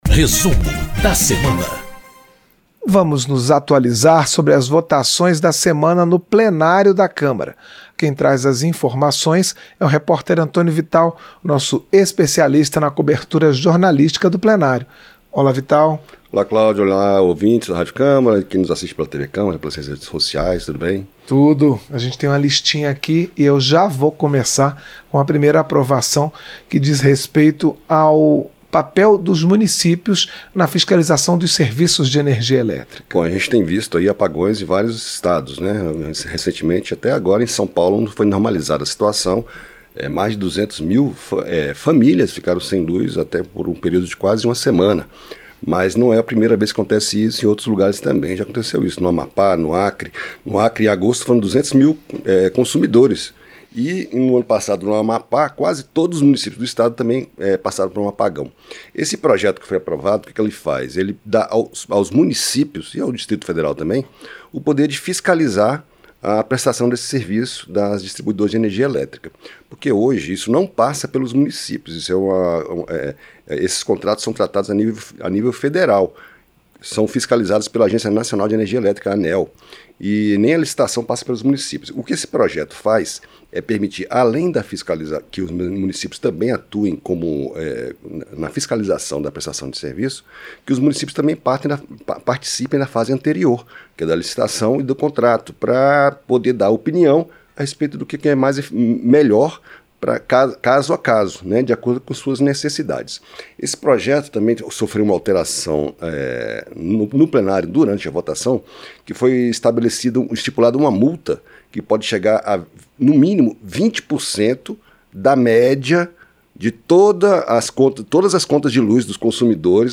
Um resumo dos principais fatos da semana nas comissões e no plenário da Câmara dos Deputados